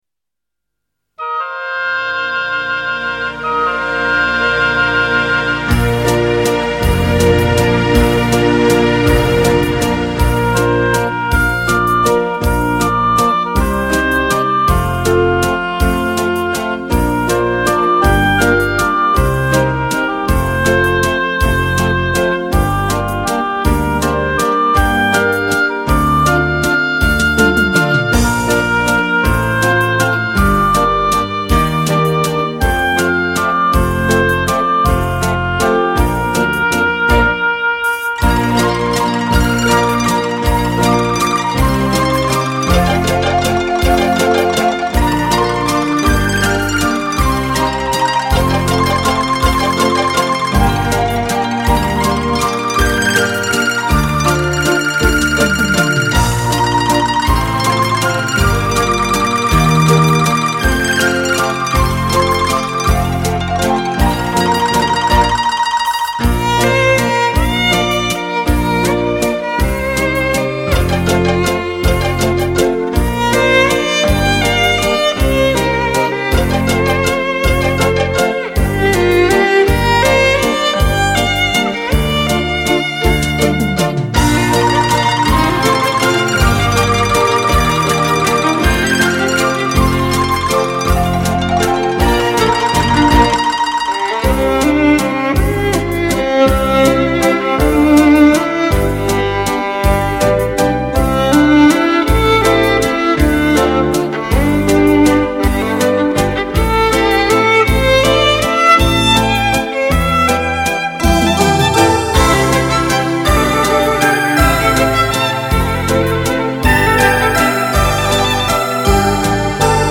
鲜明的节奏 优雅的曲风
华尔兹  源于德国，3/4拍子。舞曲婉转、激 情、优美。
快三